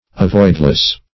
Avoidless \A*void"less\, a. Unavoidable; inevitable.